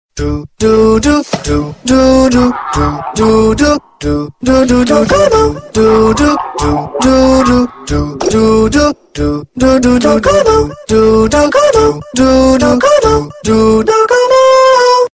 забавные
Забавная мелодия с двумя мужскими голосами